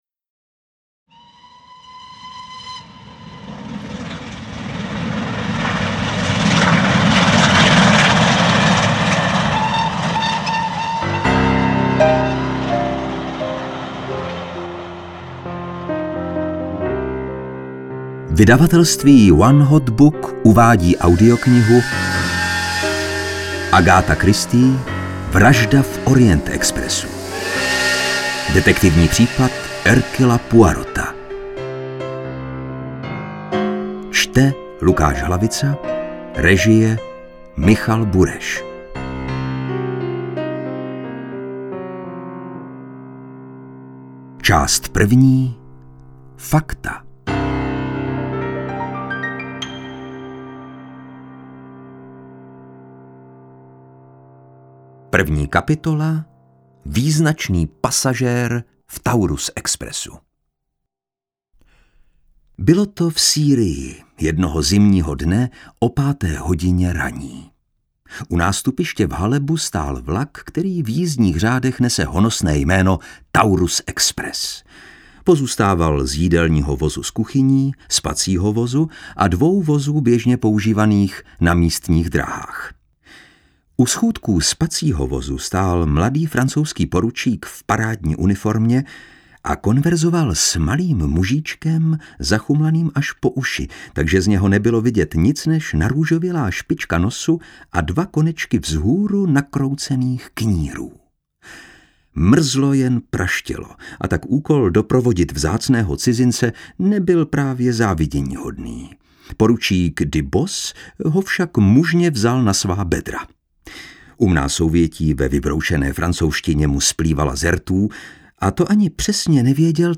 Interpret:  Lukáš Hlavica